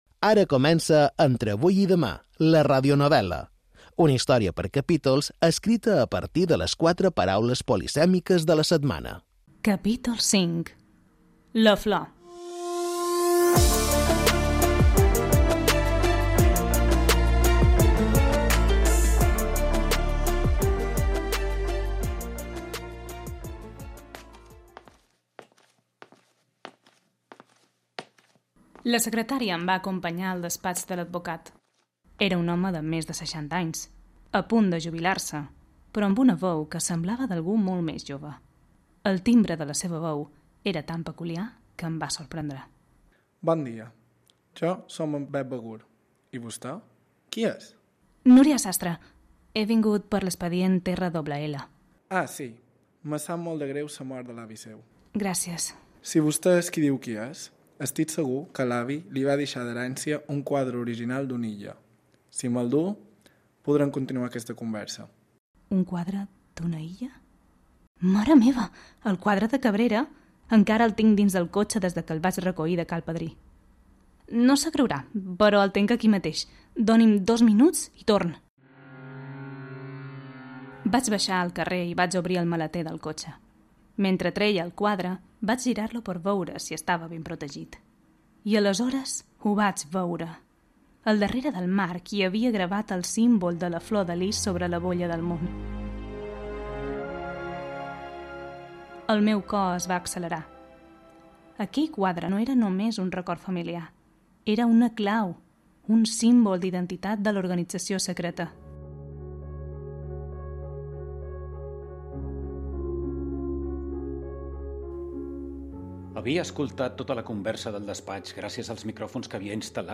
• Entre avui i demà, la radionovel·la - 5 - La Flor 7 min